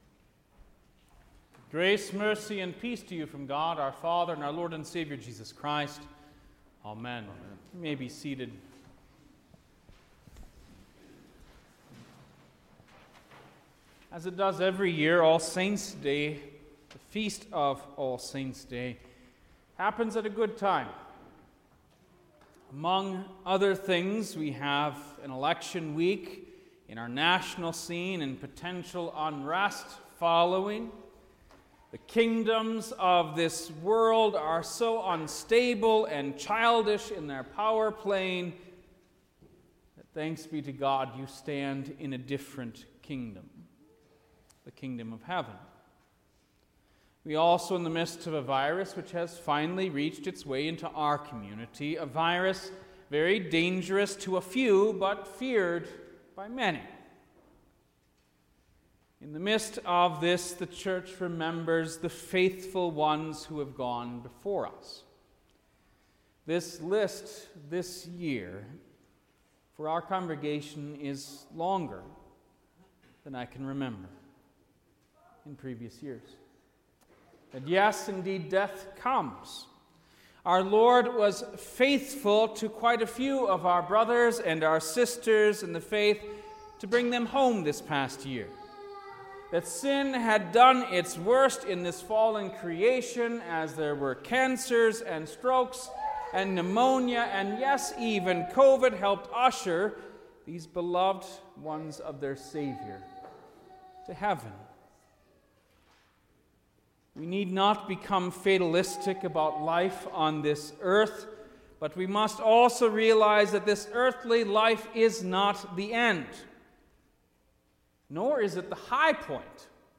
November-1-All-Saints-Day_Sermon.mp3